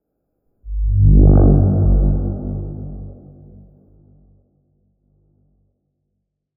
robowhoosh-electro-1b.wav